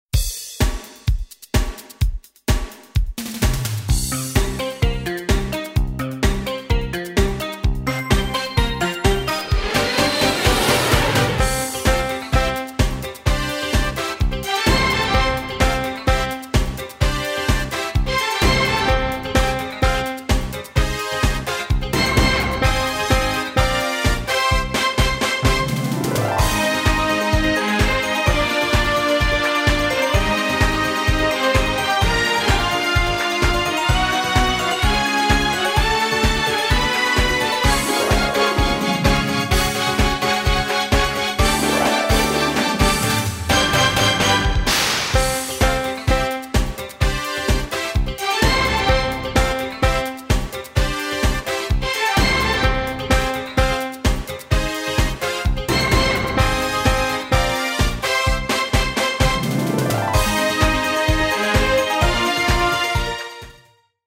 教材音樂大多為管弦樂團或熱門樂團所演奏